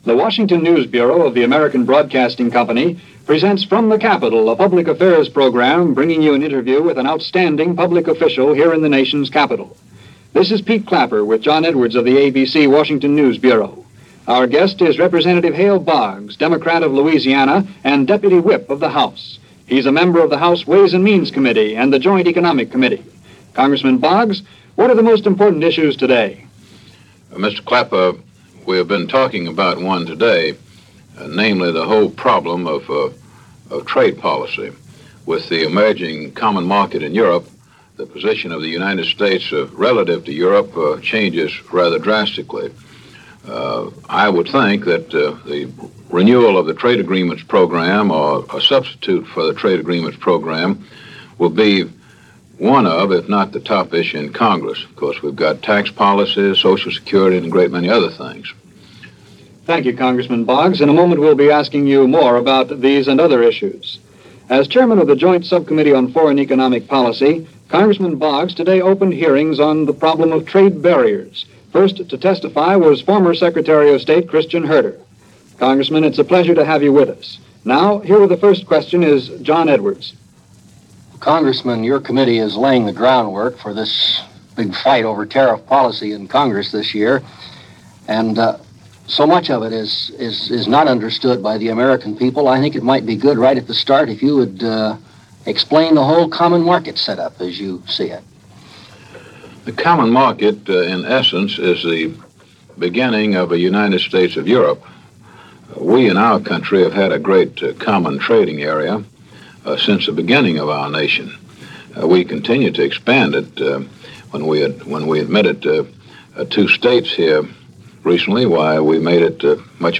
December 12, 1961 - America Views The Common Market . . .With Skepticism - discussion with Rep. Hale Boggs (D-LA) - Past Daily.